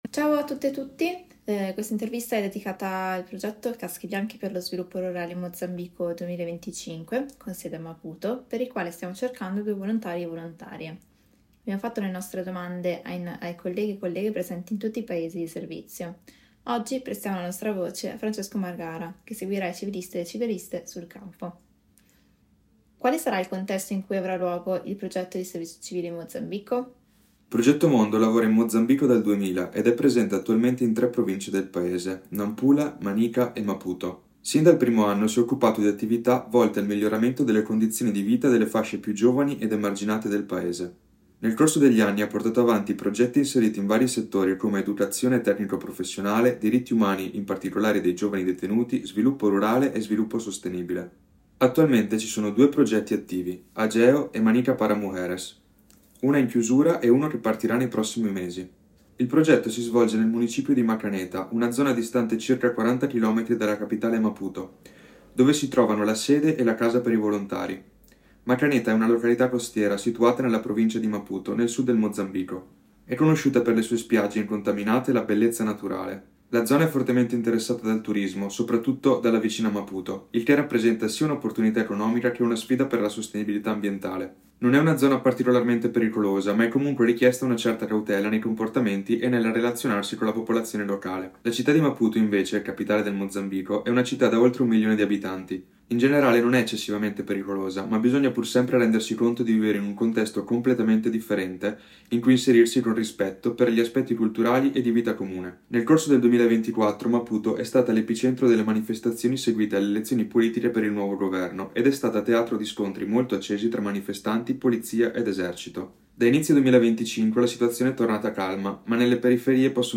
INTERVISTA 6